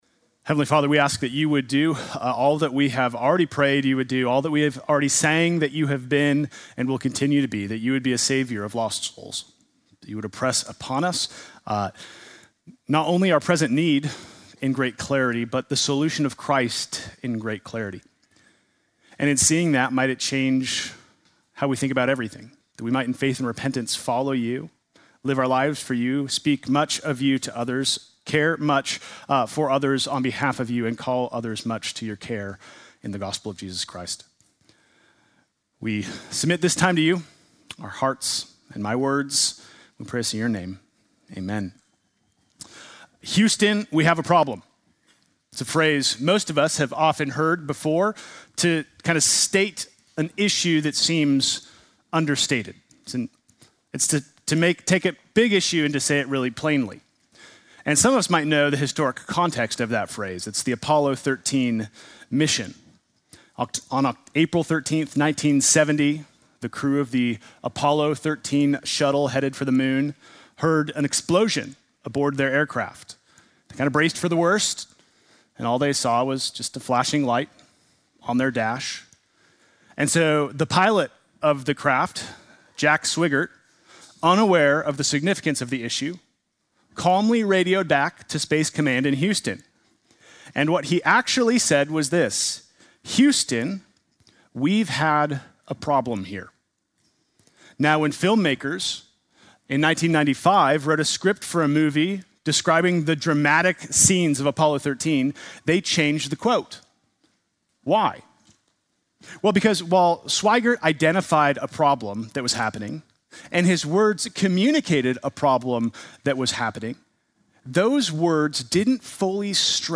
Sunday morning message November 2